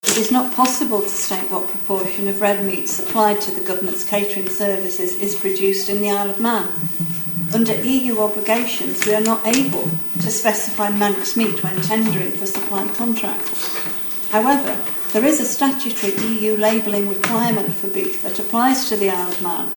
This week in the House of Keys she faced questions on the topic from Garff MHK Daphne Caine.